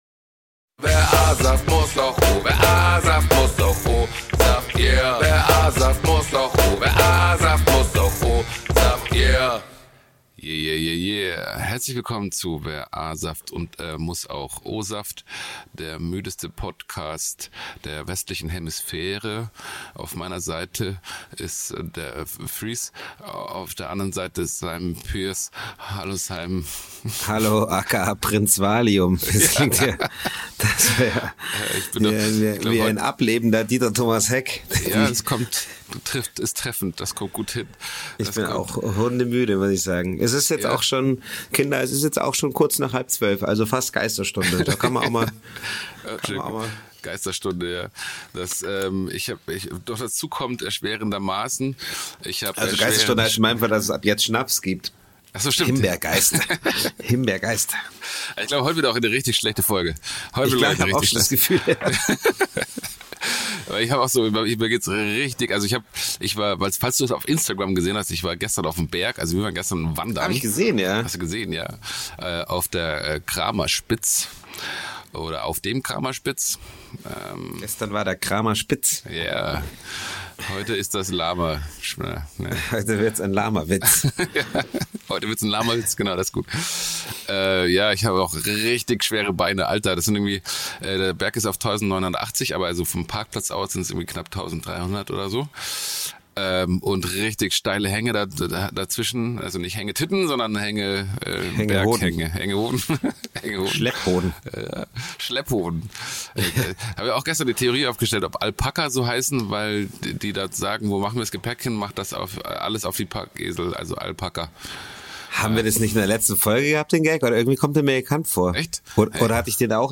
Beide müde aber trotzdem lustig.